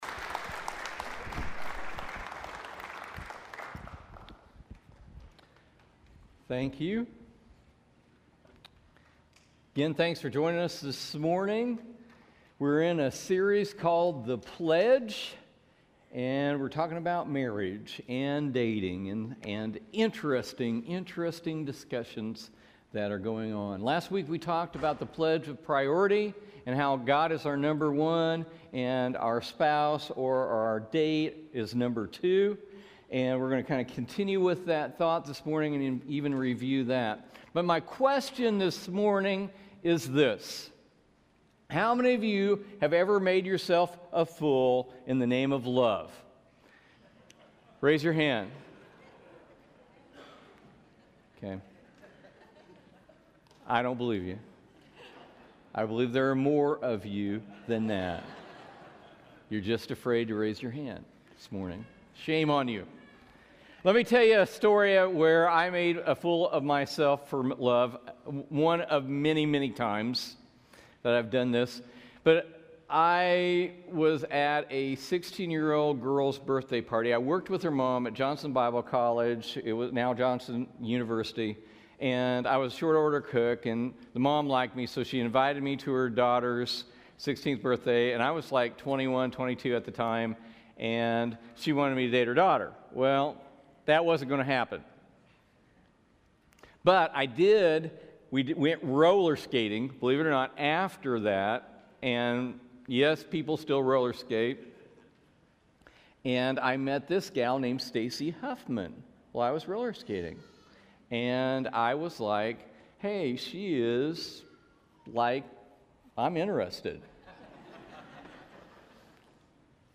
Part one of the sermon series The Pledge, a series about marriage.